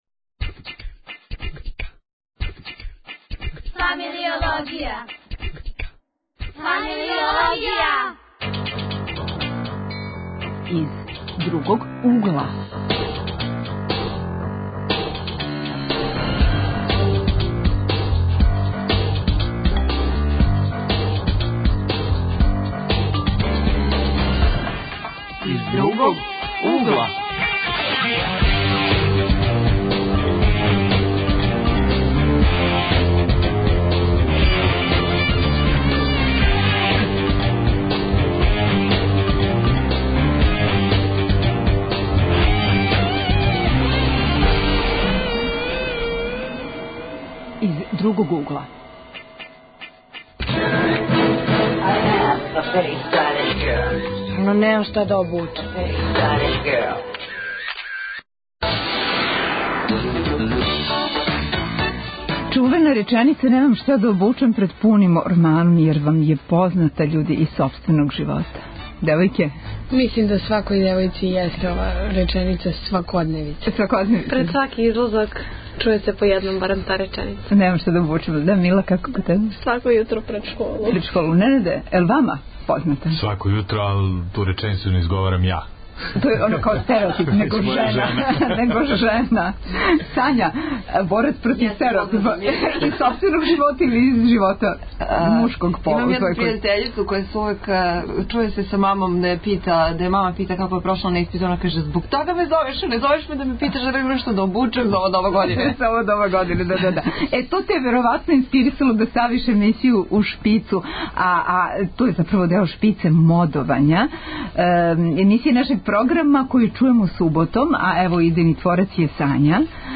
Гости: средњошколци